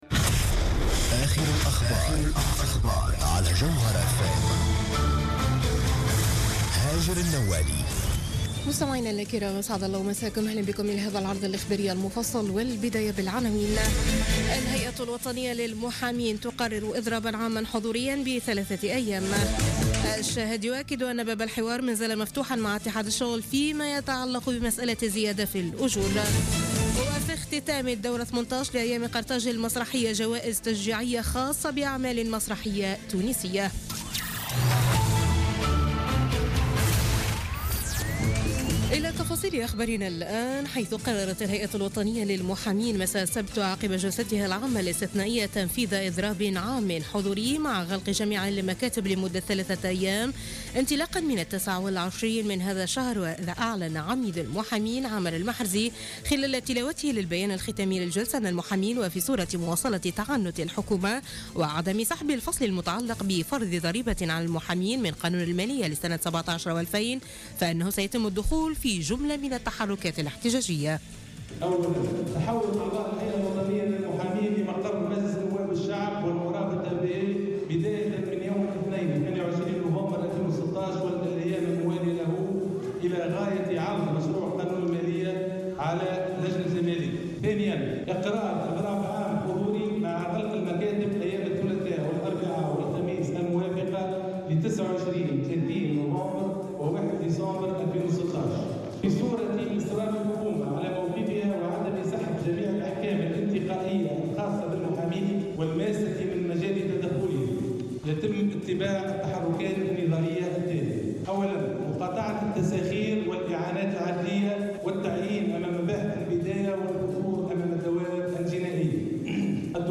نشرة أخبار منتصف الليل ليوم الأحد 27 نوفمبر 2016